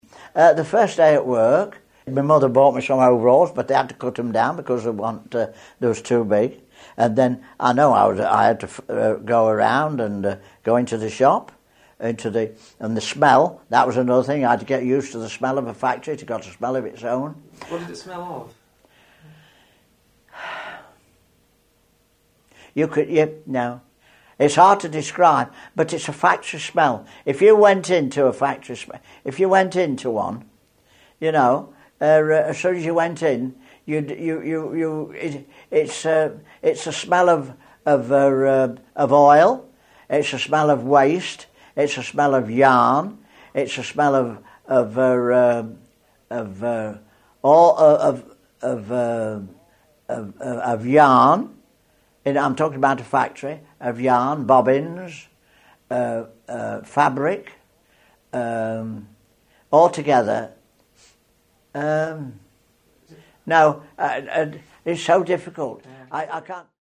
The activities in this theme explore the sights, sounds and smells of workplaces.